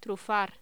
Locución: Trufar